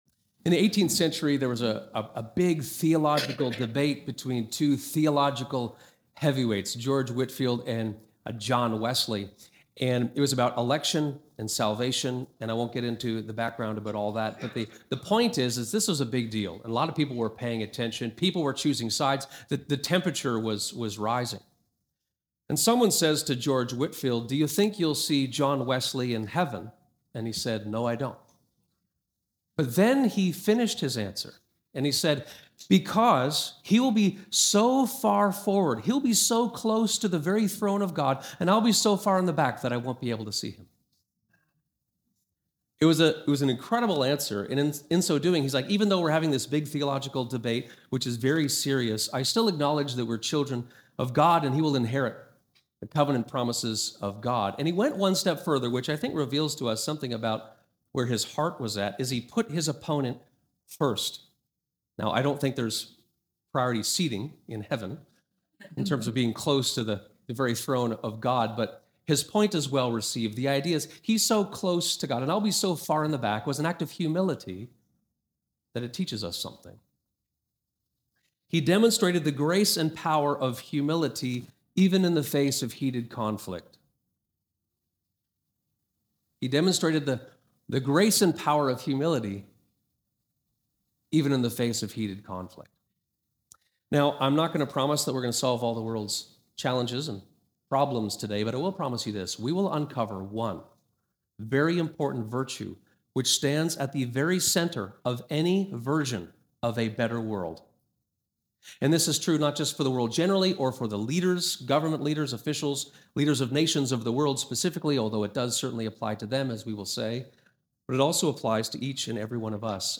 This sermon explores Daniel 4, and considers a specific character trait and posture for not only for world leaders, and for other people generally, but for each of us specifically: humility.